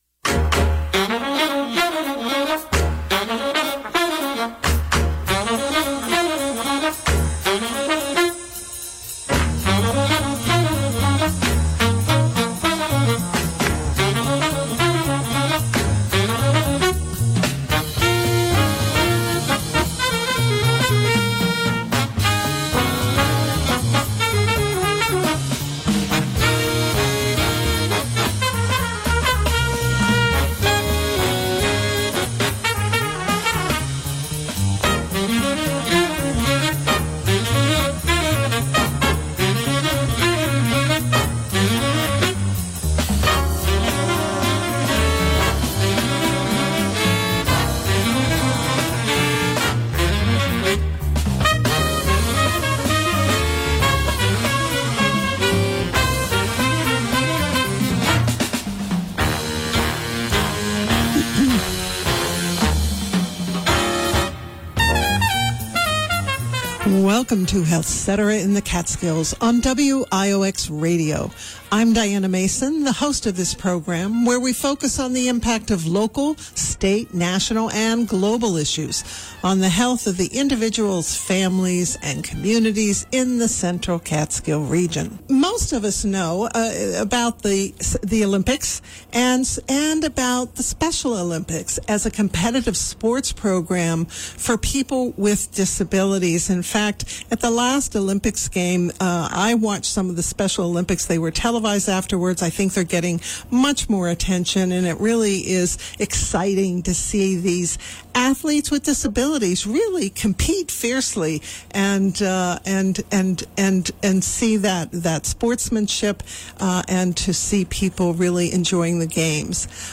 This interview first aired on WiOX Radio on July 16, 2025.